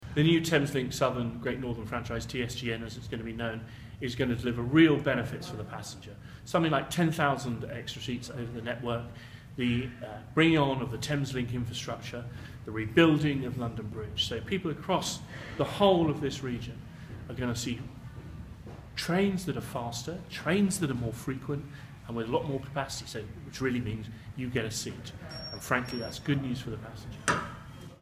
Rail minister Stephen Hammond explains how the new TSGN franchise will transform journeys across London and the South East.